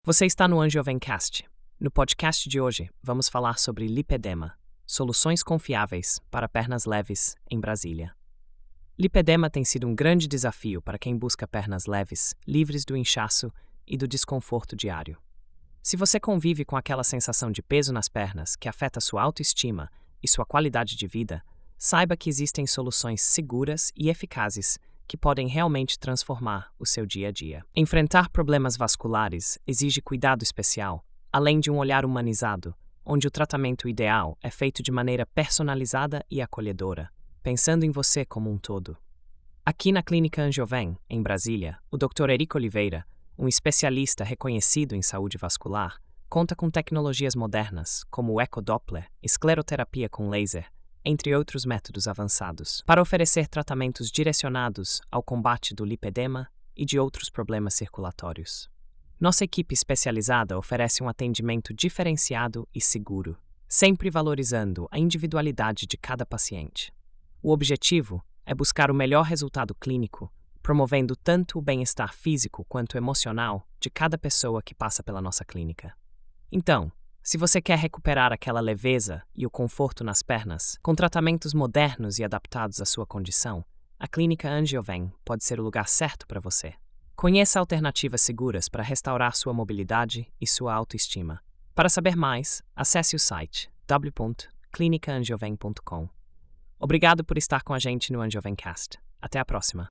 Narração automática por IA